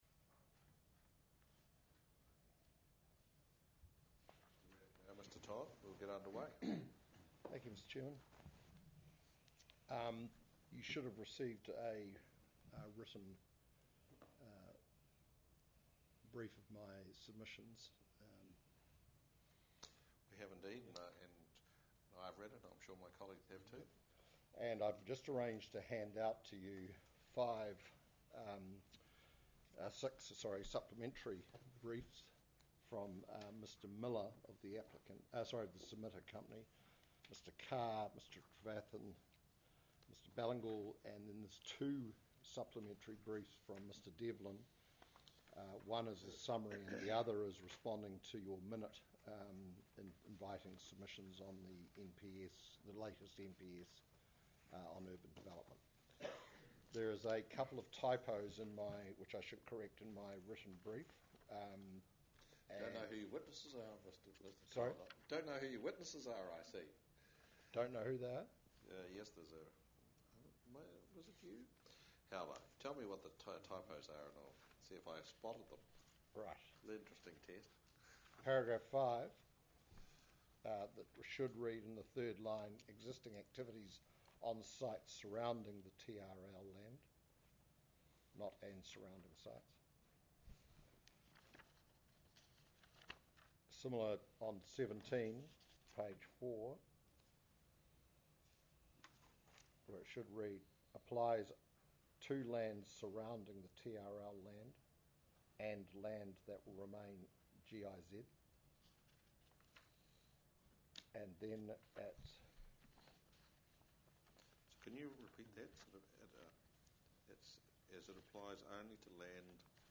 This hearing will cover Chapter 18A General Industrial Zone, Three Parks Commercial, 101 Ballantyne Road Rezoning, and Business Mixed Use and Residential Design Guide and...